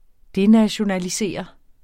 Udtale [ ˈdenaɕonaliˌseˀʌ ]